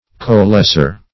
co-lessor - definition of co-lessor - synonyms, pronunciation, spelling from Free Dictionary
Co-lessor \Co`-les*sor"\, n. A partner in giving a lease.